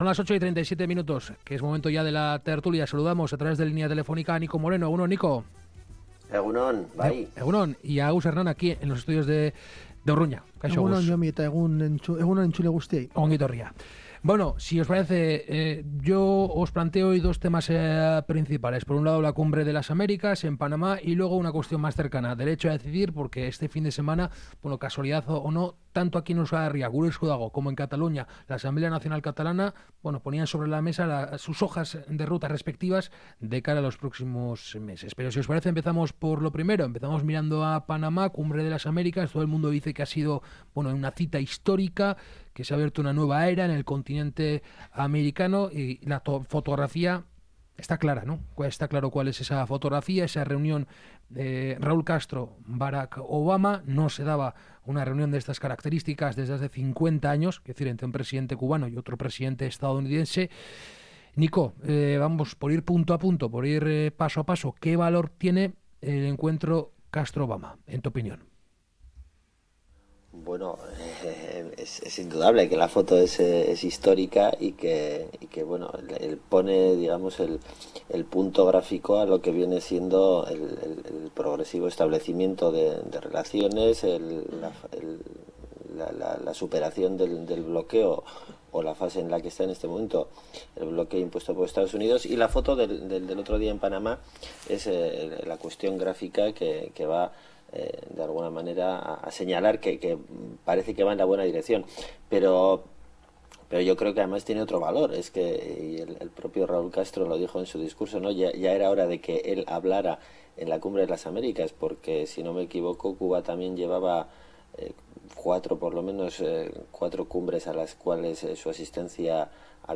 La tertulia de Kalegorrian: Cumbre de las américas, Gure Esku Dago…
Charlamos y debatimos sobre algunas de las noticias más comentadas de la semana con nuestros colaboradores habituales.